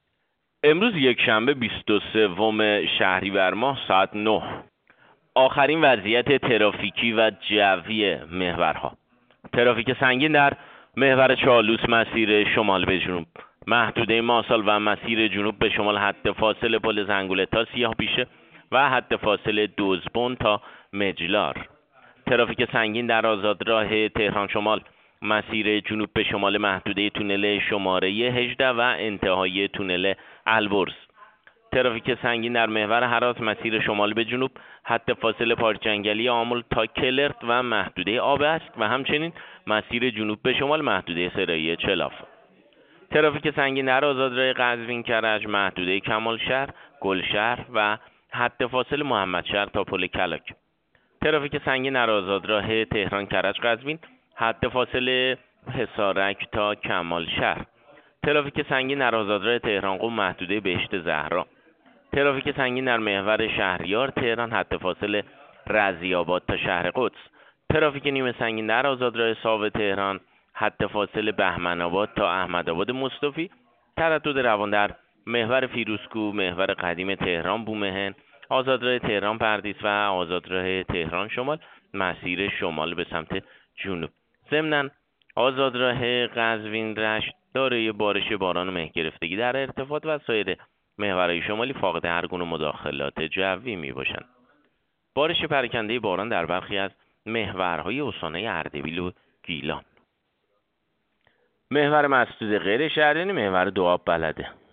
گزارش رادیو اینترنتی از آخرین وضعیت ترافیکی جاده‌ها ساعت ۹ بیست و سوم شهریور؛